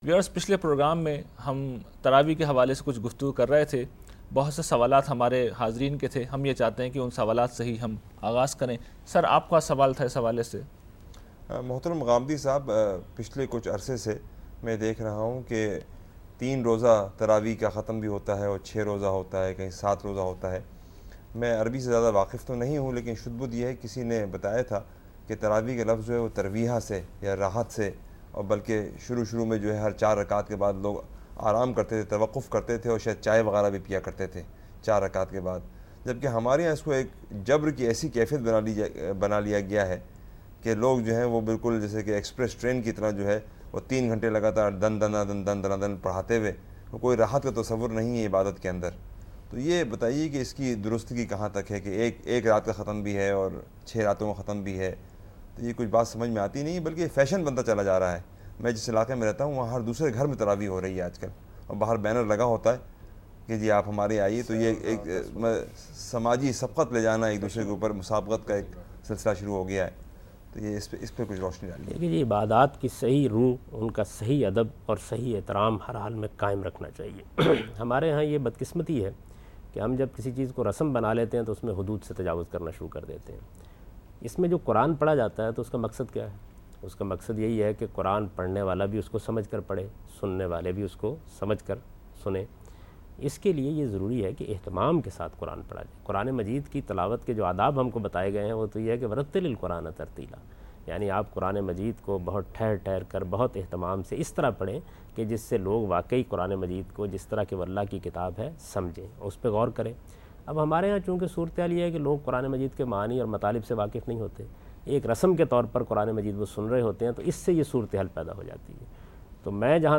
Live on Aaj TV Program ' Aaj Islam'